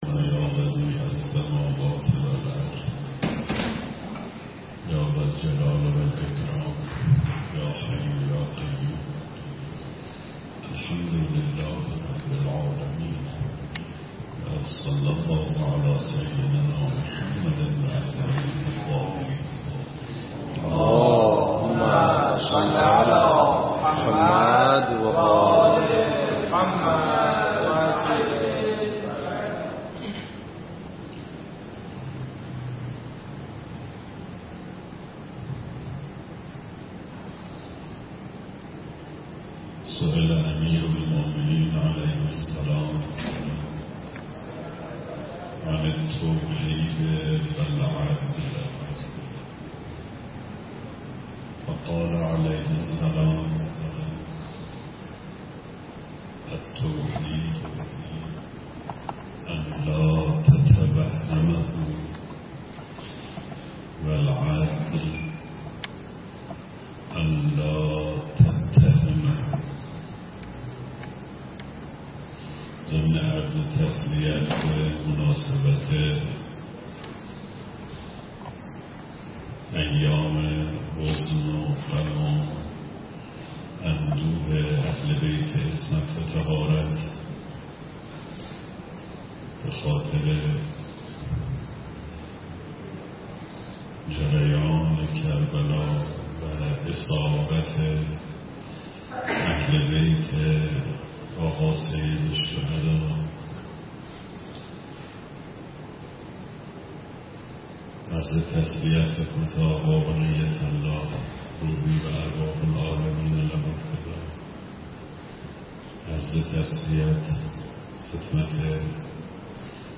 🔰مراسم اختتامیه دوره های تابستانه سال 1402 مدرسه عالی امام حسین علیه السلام